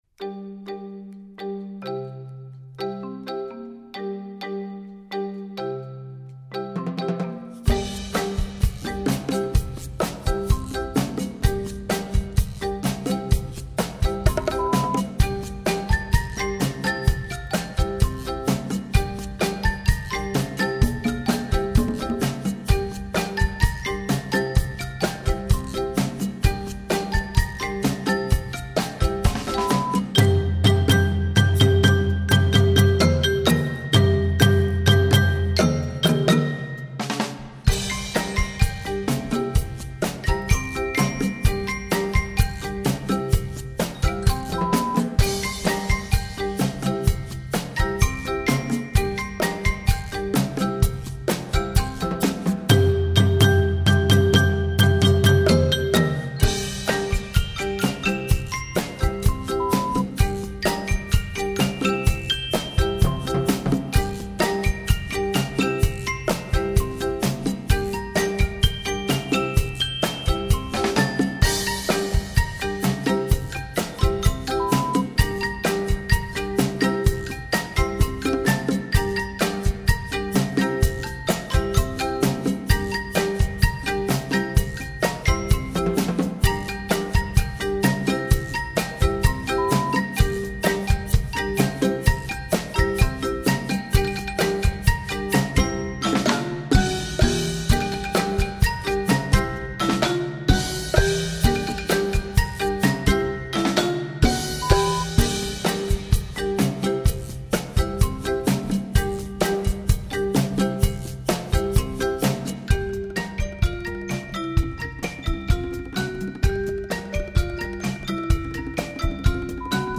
Besetzung: Instrumentalnoten für Schlagzeug/Percussion
MARIMBA 1 (2)
XYLOPHONE 1 (2)
BONGOS (2)
SHAKER (2)
GUIRO (2)
CONGAS (2)
DRUM SET (2)
TIMBALES (2)
BELLS (2)
VIBES (2)